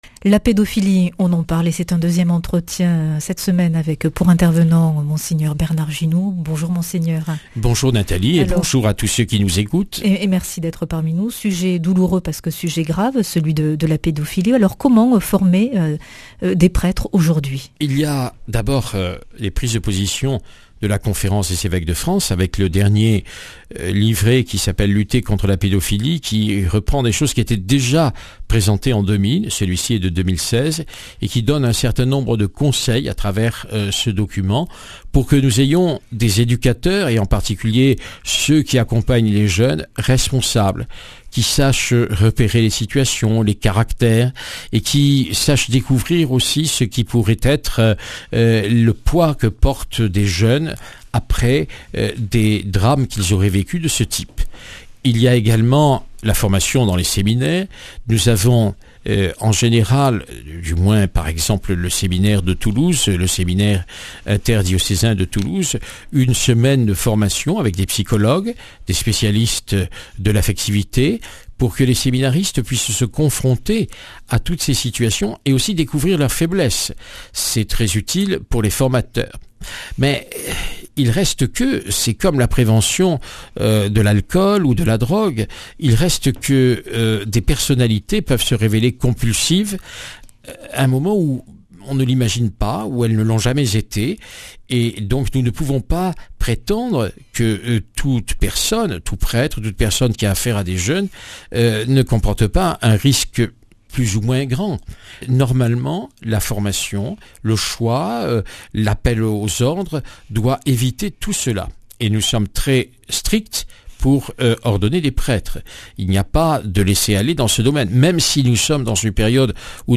Eglise et pédophilie : Comment former les prêtres aujourd’hui ?Invité de la semaine : Mgr Bernard Ginoux ,
Speech